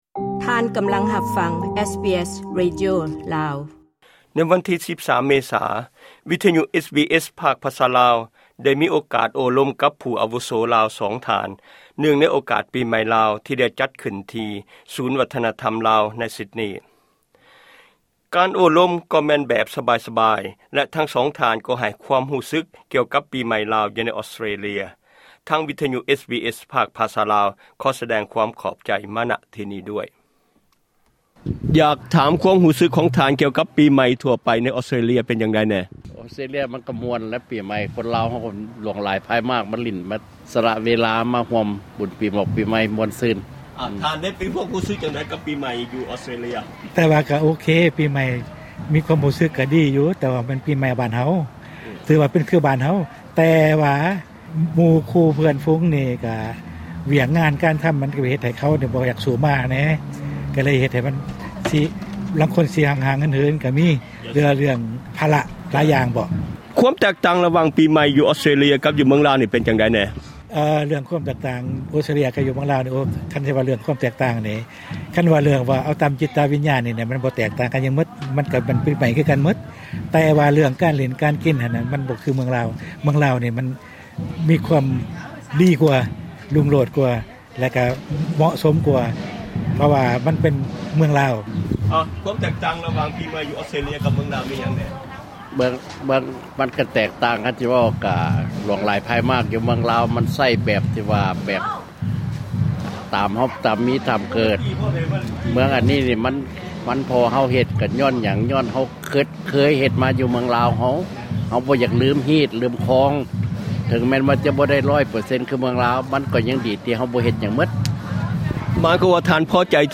ສຳພາດກ່ຽວກັບປີໃໝ່
ເນື່ອງໃນໂອກາດງານປີໃໝ່, ວິທຍຸ SBS ພາກພາສາລາວ ໄດ້ໂອ້ລົມແບບກັນເອງກັບຜູ້ອາວຸໂສ ກ່ຍວກັບຄວາມຮູ້ສຶກຂອງປີໃໝ່ລາວໃນ ອອສເຕຣເລັຍ.
ສຳພາດຜູ້ອາວຸໂສລາວໃນ ຊິດນີ ກ່ຽວກັບປີໃໝ່.